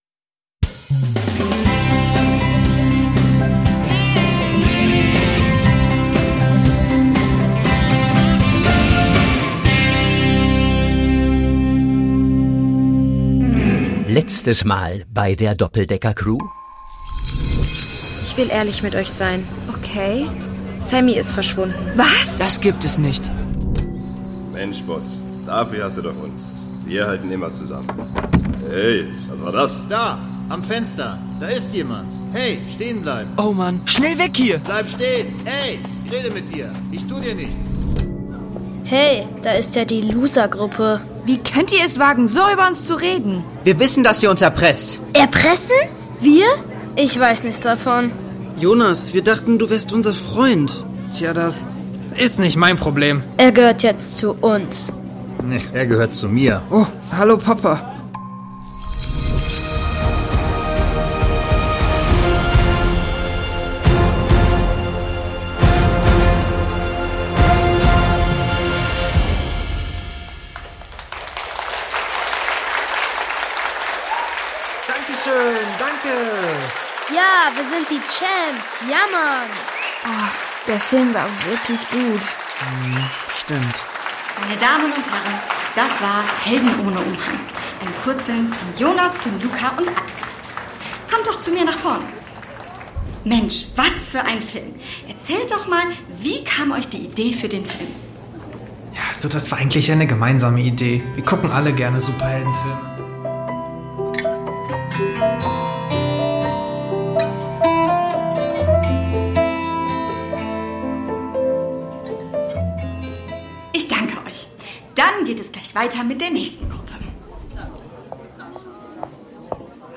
Download - Brasilien 4: Rio bei Nacht | Die Doppeldecker Crew | Hörspiel für Kinder (Hörbuch) | Podbean